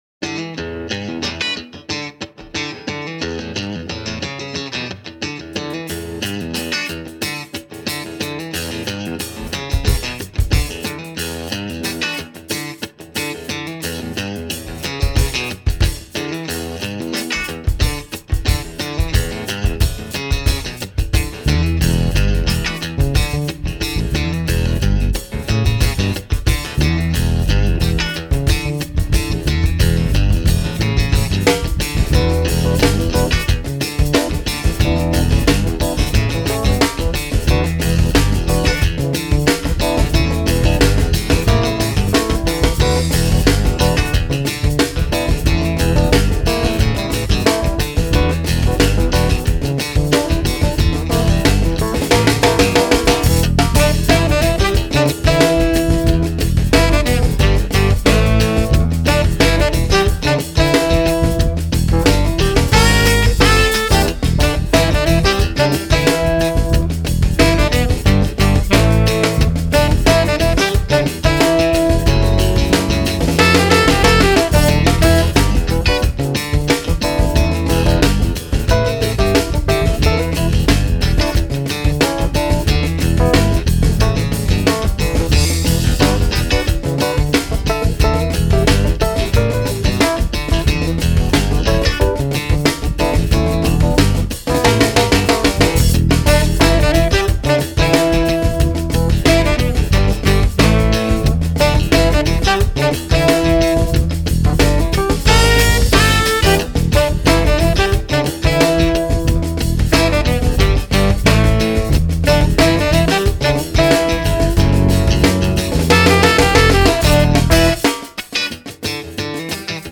saxes / flute / bassclarinet
keys
guitars
bass
drums